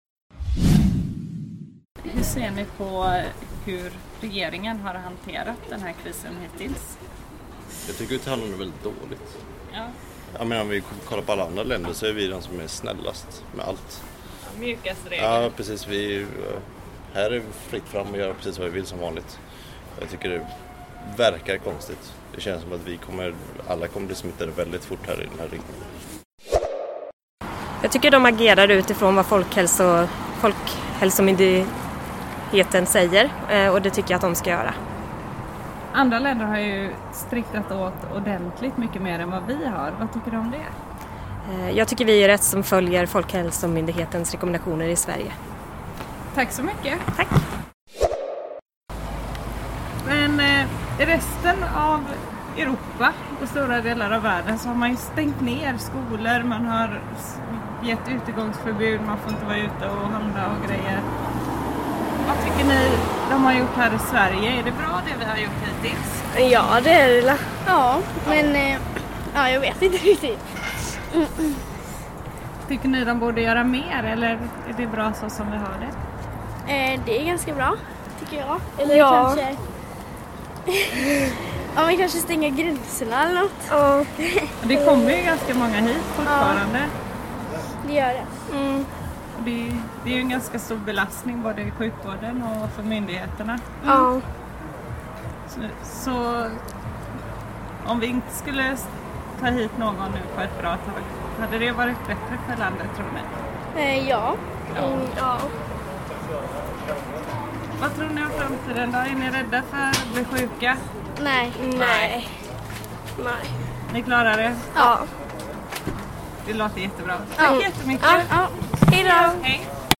REPORTAGE:
Ett reportage då vanligt folk i från Västsverige får komma till tals i frågan om hur Sverige hanterar smittspridningen av coronaviruset. Ur Coronabunkern 10/4